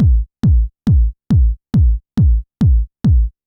Index of /90_sSampleCDs/Best Service ProSamples vol.54 - Techno 138 BPM [AKAI] 1CD/Partition C/UK PROGRESSI
BD        -L.wav